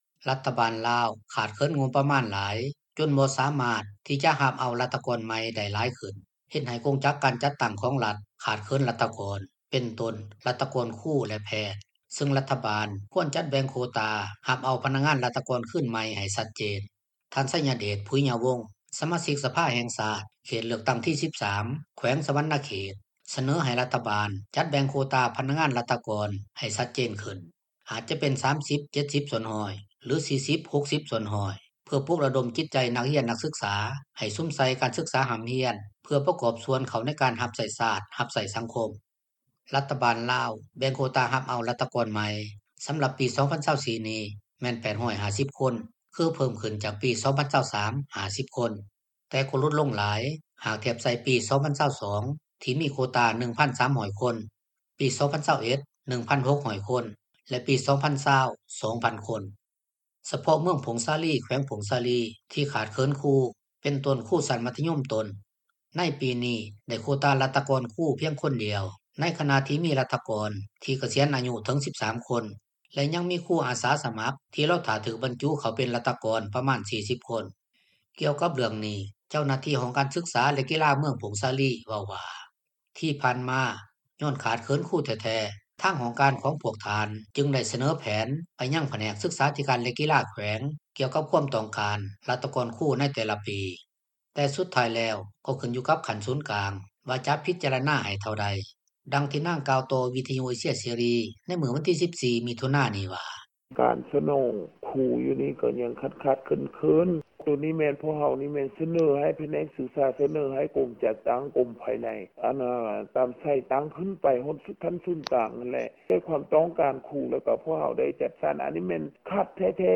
ດັ່ງທີ່ນາງກ່າວຕໍ່ ວິທຍຸເອເຊັຽເສຣີ ໃນມື້ວັນທີ 14 ມິຖຸນານີ້ວ່າ: